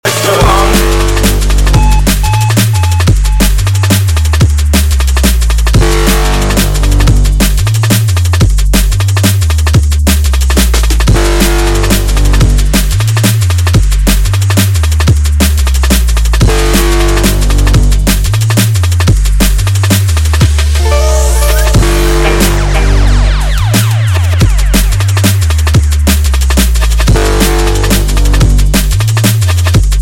TOP >Vinyl >Drum & Bass / Jungle
Dub Mix